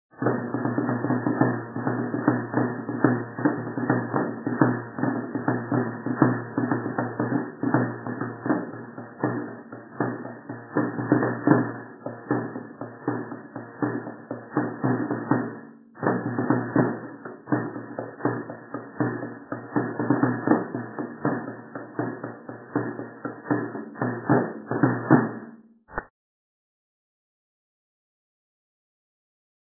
11)  Toque de jota creado por él (comienza por el normal y sigue con el suyo)